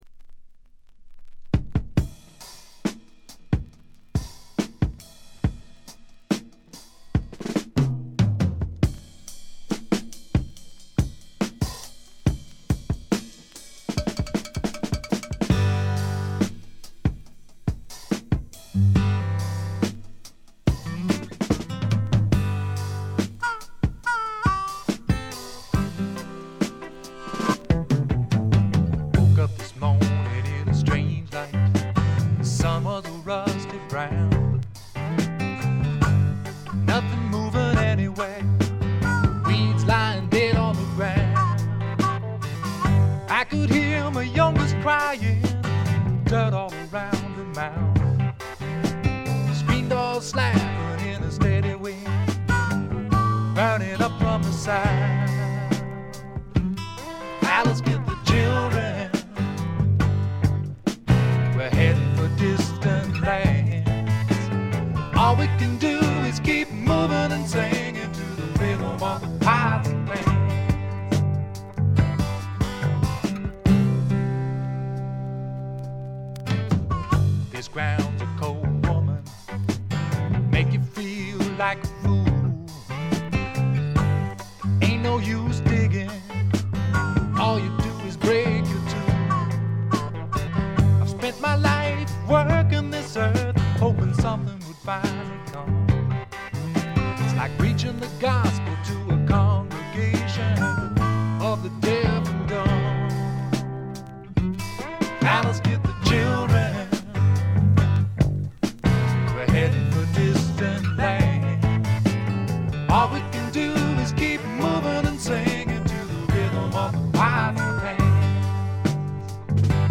軽微なチリプチ。散発的なプツ音少し。
シンプルなバックに支えられて、おだやかなヴォーカルと佳曲が並ぶ理想的なアルバム。
試聴曲は現品からの取り込み音源です。